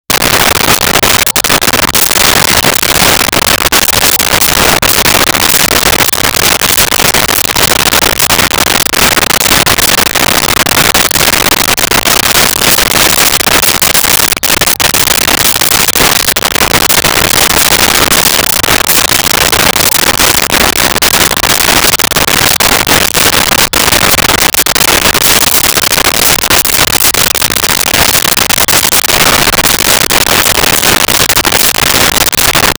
Suburban Neighborhood
Suburban Neighborhood.wav